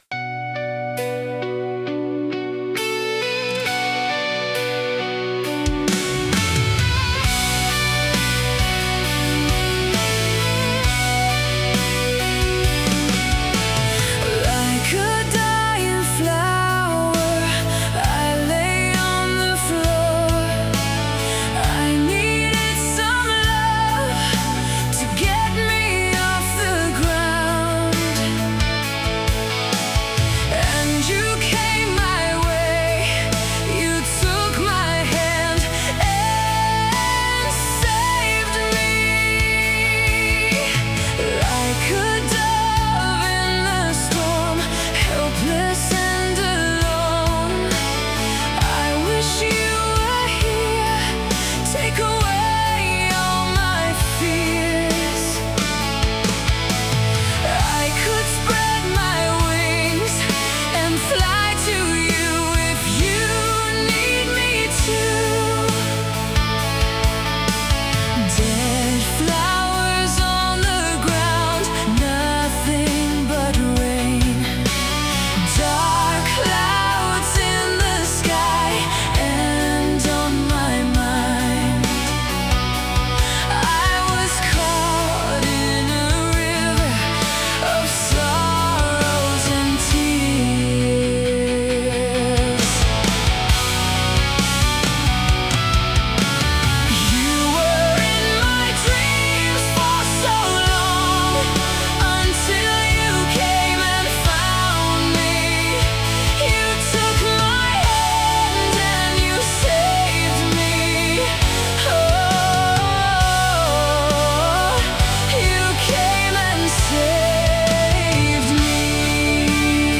All songs on this page are Demo-Songs by EH Production